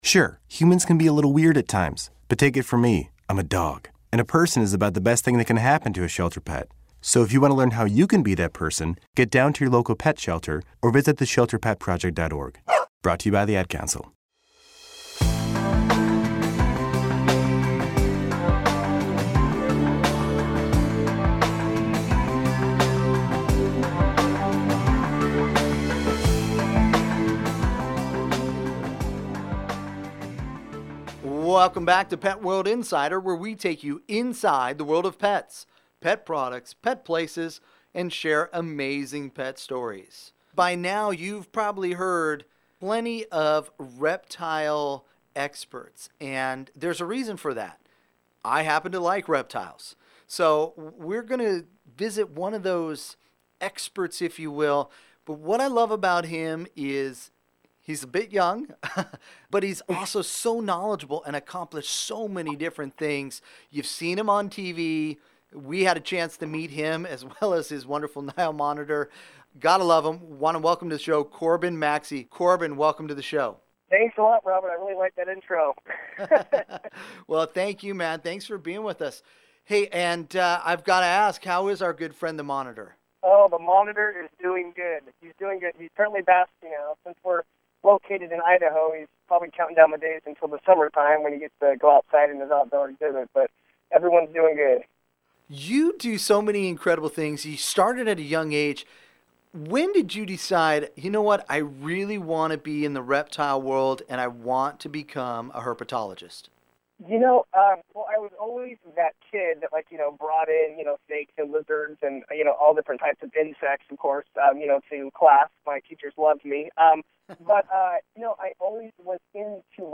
On this Pet World Radio Segment we sit down with one of our favorite reptile and animal experts